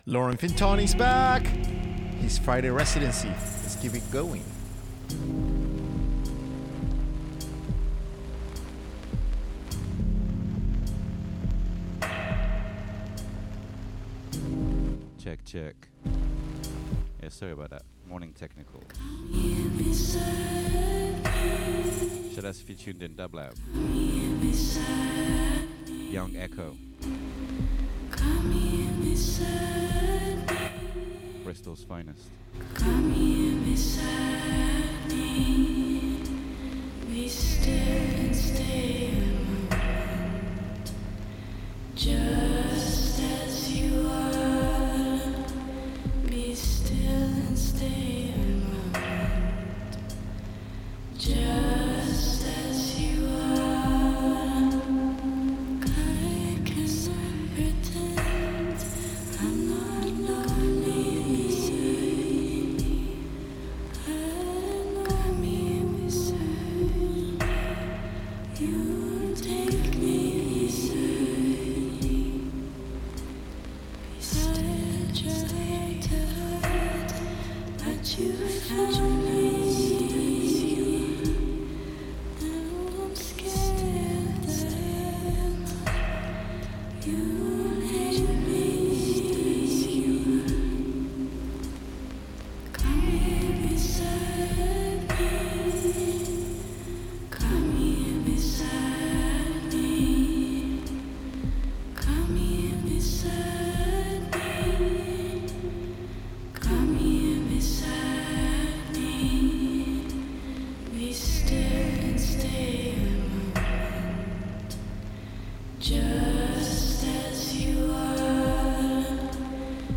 Beats Electronic Hip Hop